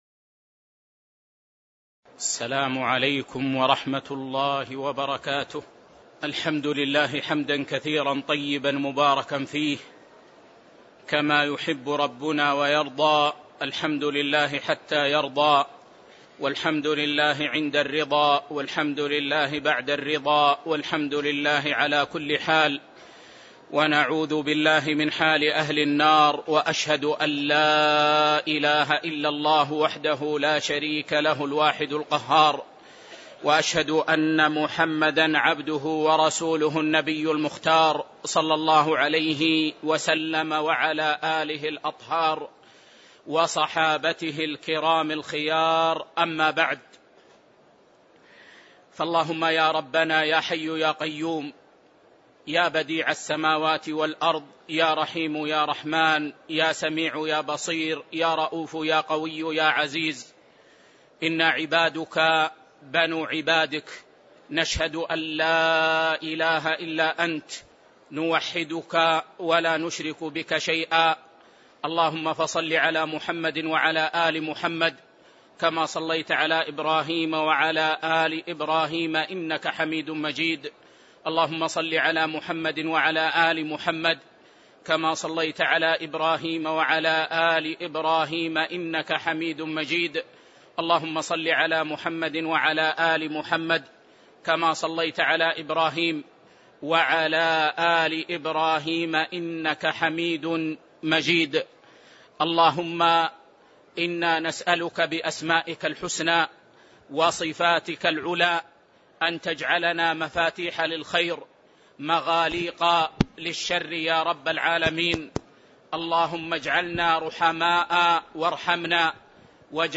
تاريخ النشر ٢١ ربيع الأول ١٤٣٧ هـ المكان: المسجد النبوي الشيخ